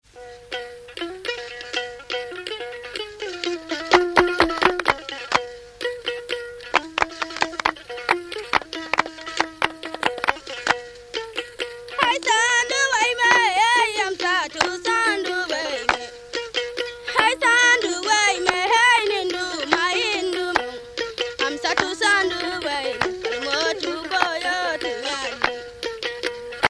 circonstance : fiançaille, noce
Pièce musicale éditée